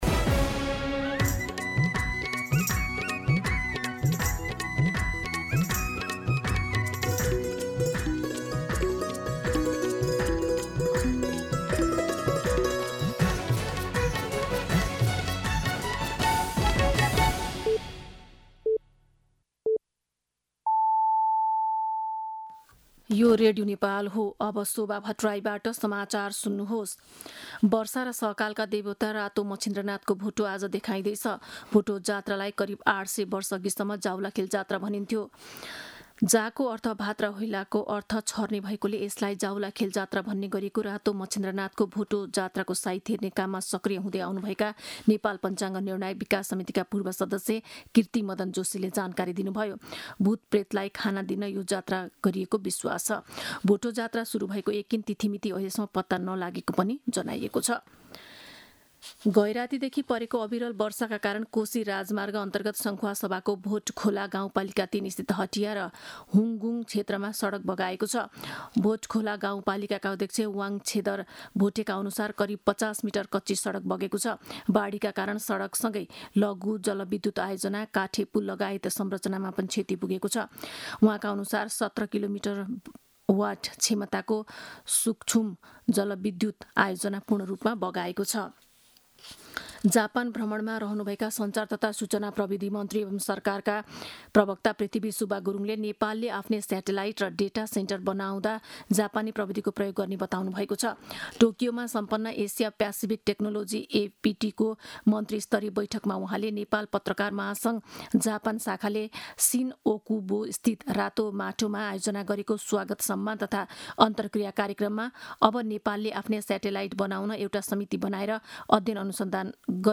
दिउँसो ४ बजेको नेपाली समाचार : १८ जेठ , २०८२
4pm-News-02-18.mp3